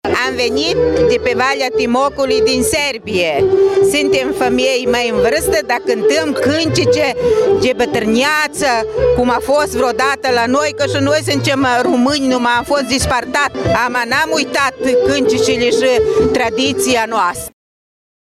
Programul de cântece şi dansuri a fost susţinut de 8 formaţii de cehi, slovaci, germani, ucraineni şi sârbi din judeţ, dar şi din Banatul sârbesc.